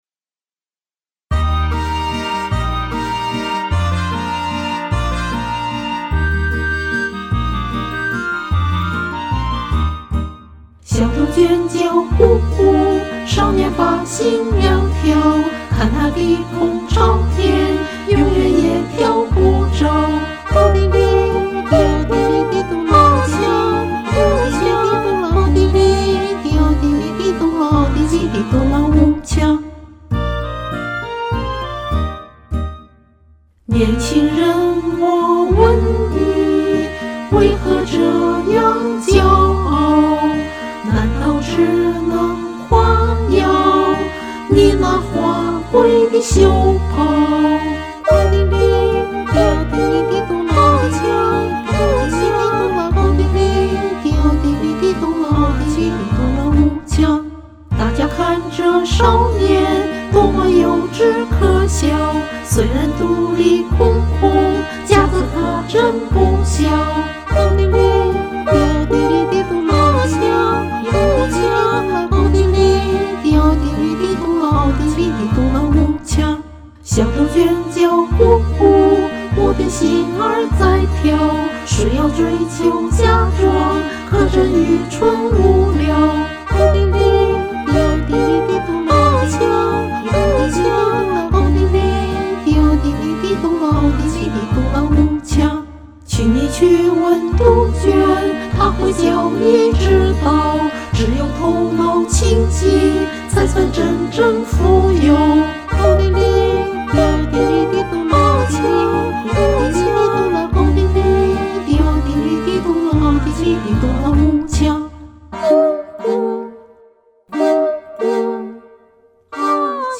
《小杜鹃》----波兰民歌
我最近忽然腰疼，就想唱首没什么力度的歌。在网上搜伴奏时，偶尔看到这首歌的伴奏，那就唱它。